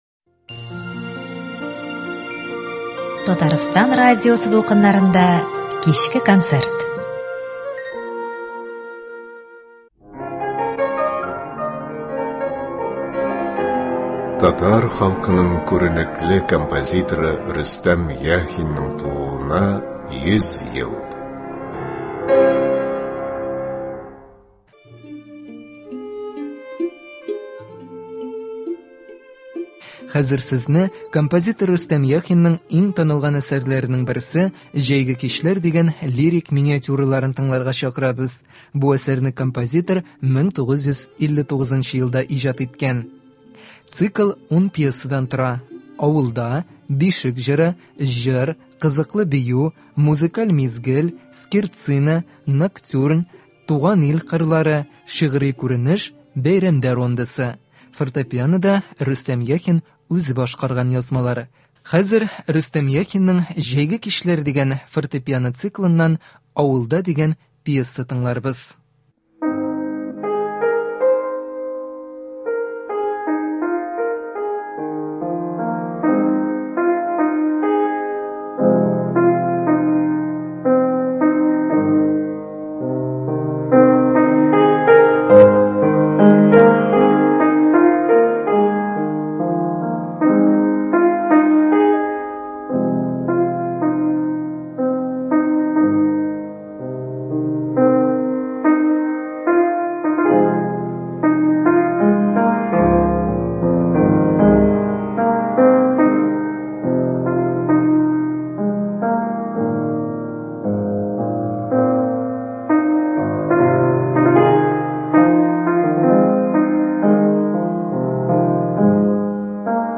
Бүгенге кичке концертта яраткан җырларыбыз яңгырый.